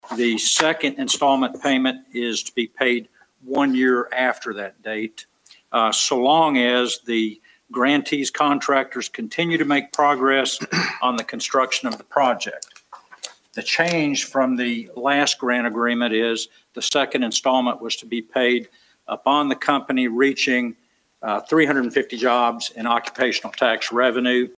Tuesday night, Hopkinsville City Council heard an amended version of the agreement. City Attorney Doug Willen says there is a change to the payment schedule.